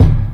Kick 2.wav